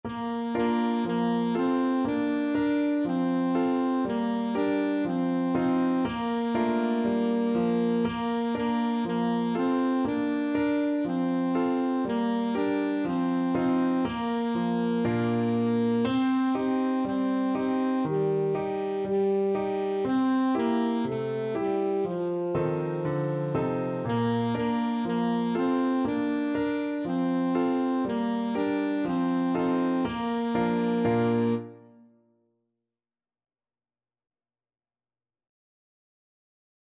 Traditional Trad. Au claire de la lune Alto Saxophone version
Bb major (Sounding Pitch) G major (Alto Saxophone in Eb) (View more Bb major Music for Saxophone )
Allegro (View more music marked Allegro)
Traditional (View more Traditional Saxophone Music)